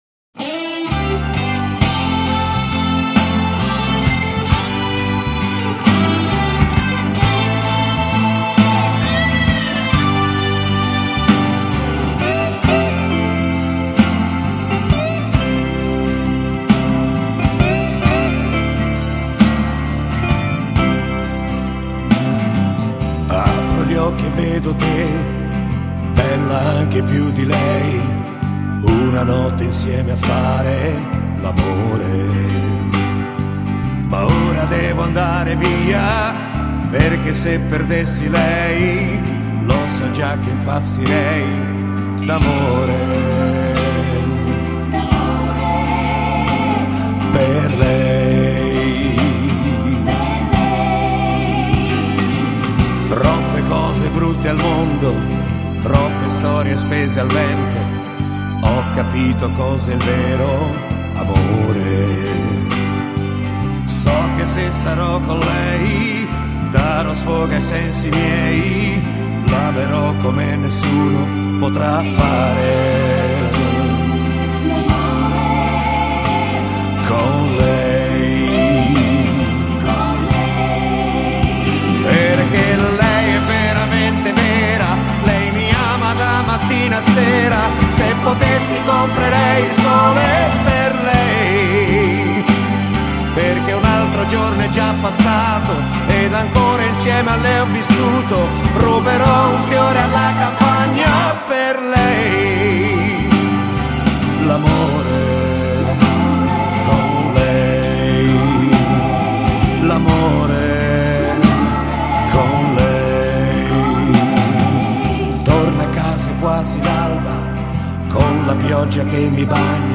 Genere: Blues Terzinato